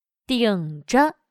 顶着/Dǐngzhe/llevar，con soporte para la cabeza